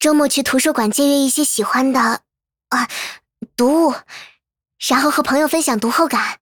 【模型】GPT-SoVITS模型编号062_女-secs